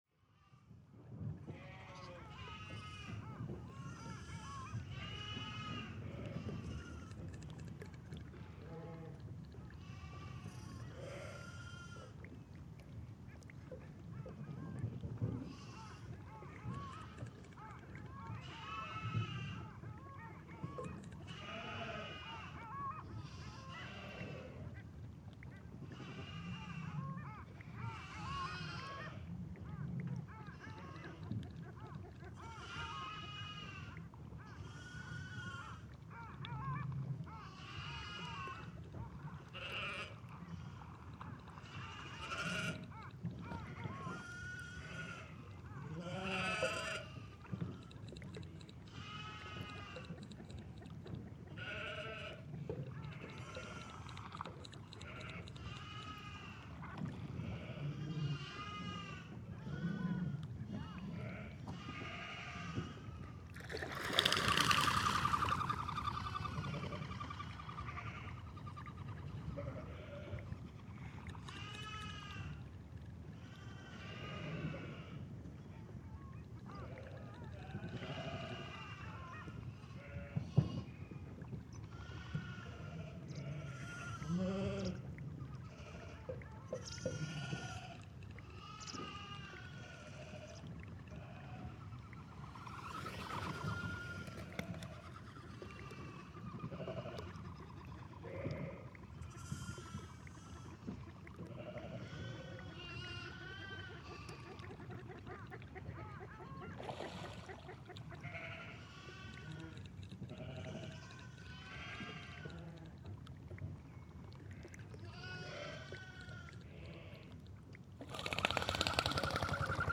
Farmers were busy in lambing and birds in mating, especially Barrow´s Goldeneye.